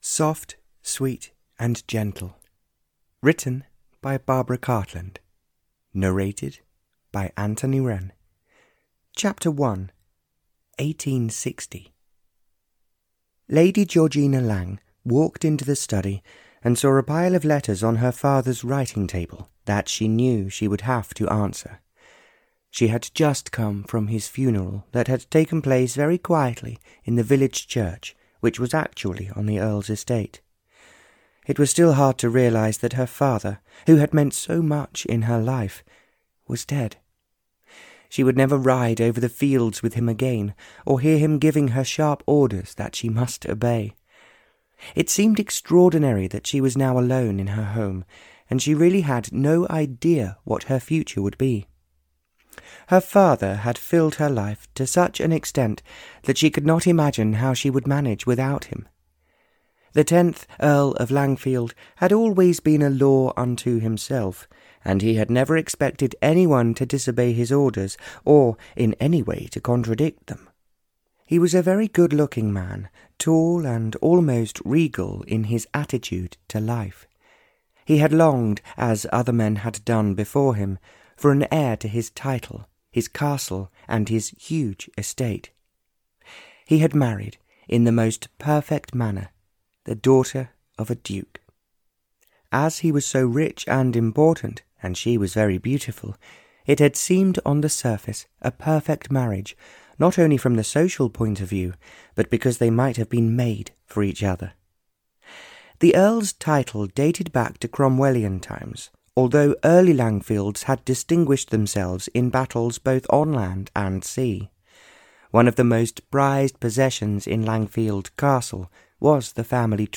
Audio knihaSoft, Sweet & Gentle (Barbara Cartland's Pink Collection 107) (EN)
Ukázka z knihy